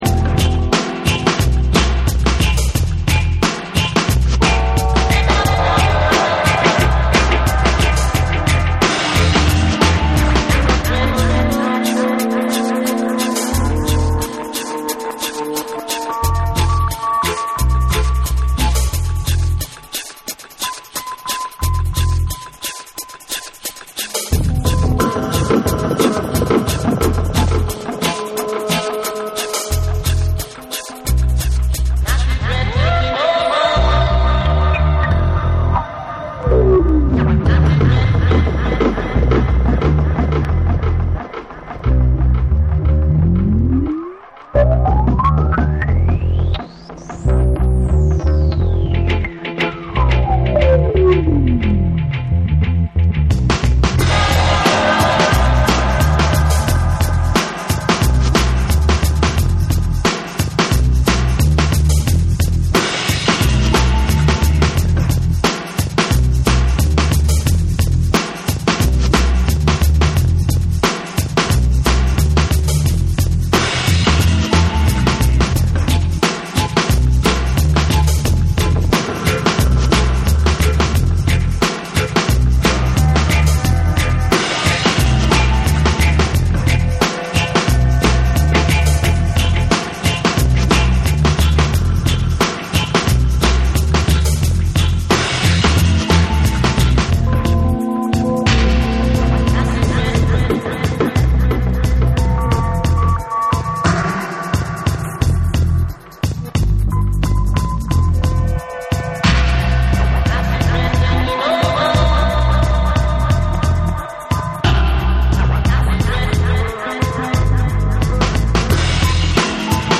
TECHNO & HOUSE / REGGAE & DUB